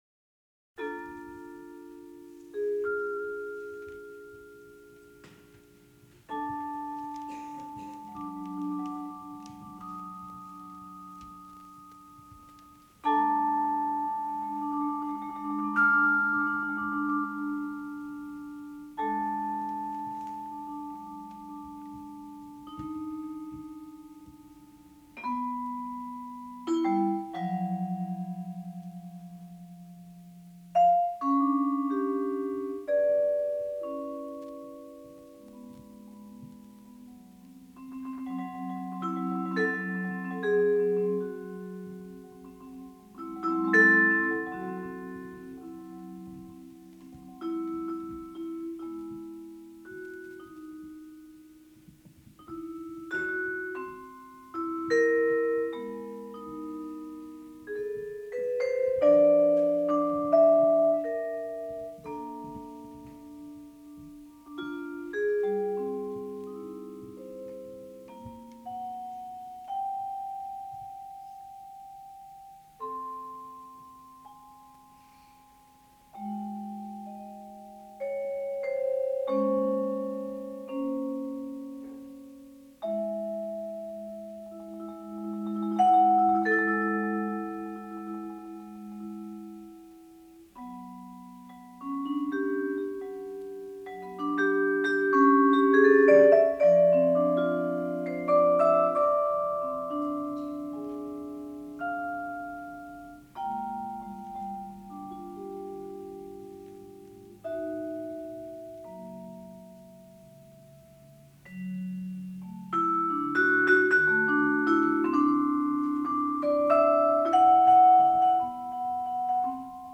Bass, Flute [Odurogyaba]
Tenor Saxophone, Soprano Saxophone, Flute
Trumpet, Flugelhorn, Flute [Atenteben]
Vibraphone